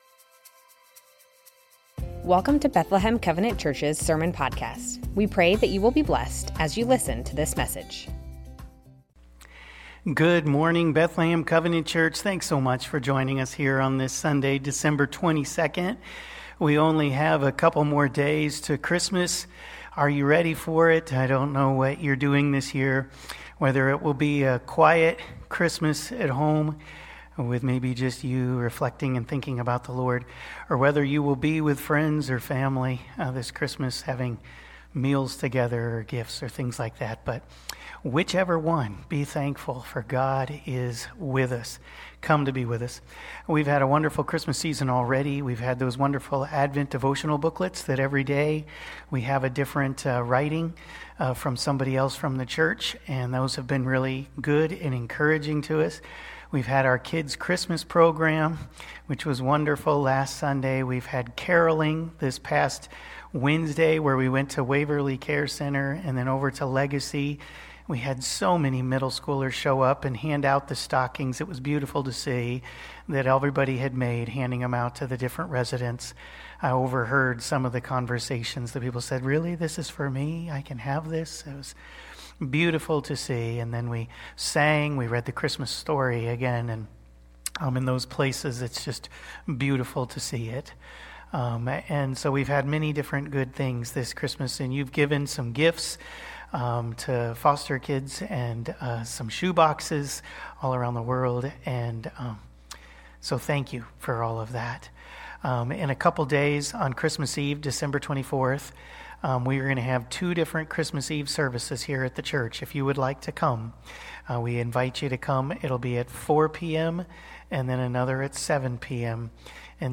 Bethlehem Covenant Church Sermons Advent - In the Name of Jesus Dec 22 2024 | 00:34:30 1x 00:00 / 00:34:30 Subscribe Share Spotify RSS Feed Share Link Embed